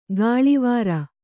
♪ gāḷivāra